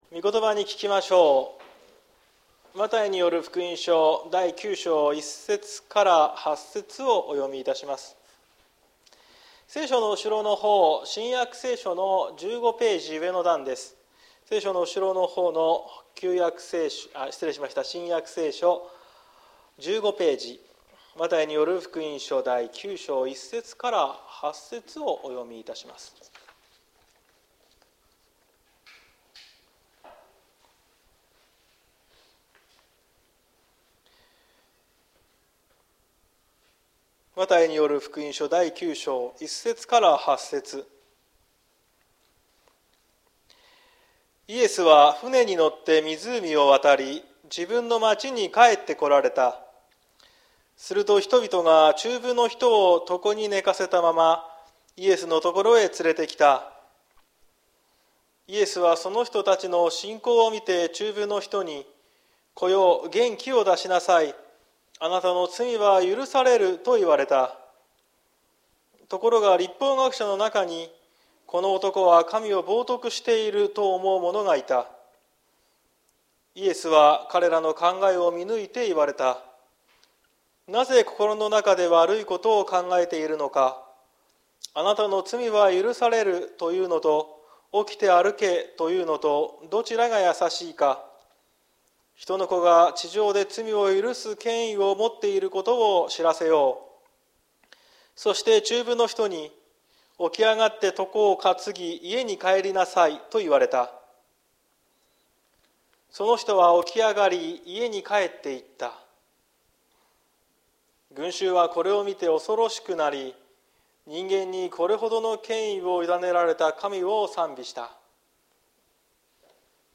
2023年07月30日朝の礼拝「群衆の驚き」綱島教会
説教アーカイブ。